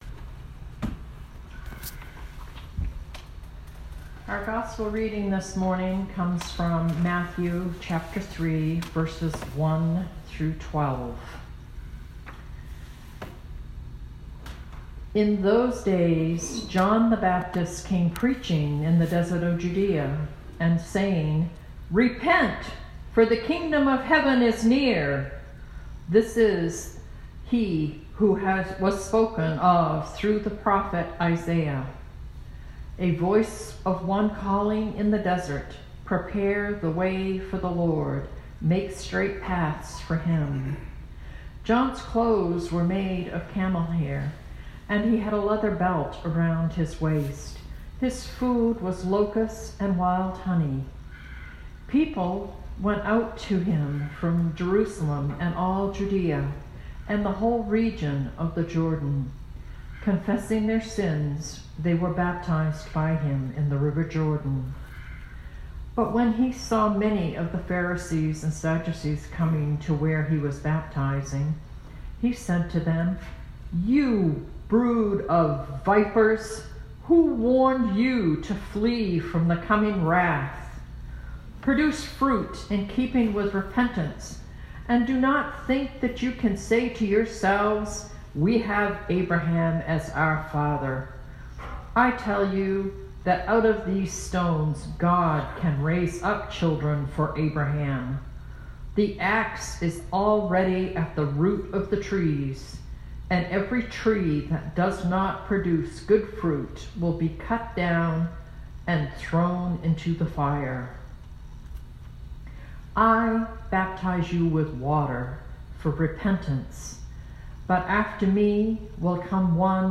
Sermon 2012-12-08